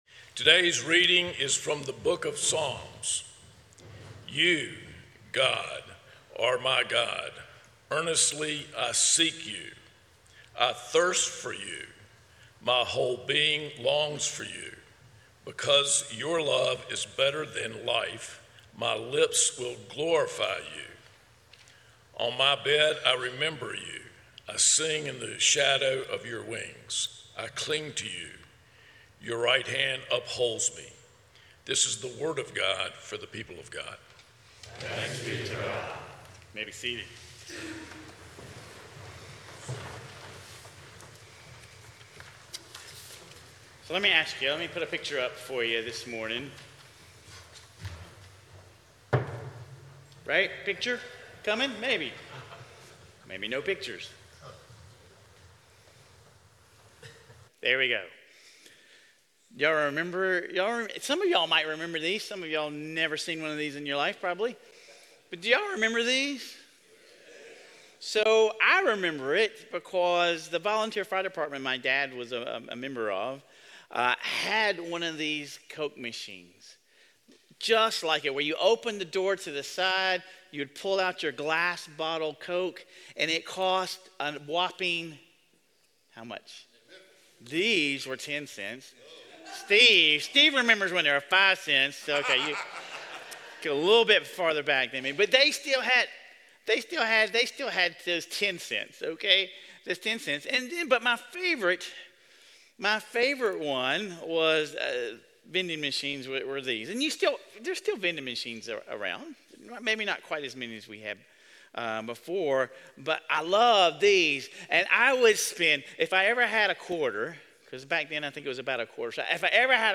Sermon Reflections: In what ways have you been treating God like a "vending machine" – expecting specific outcomes in exchange for good behavior or prayers?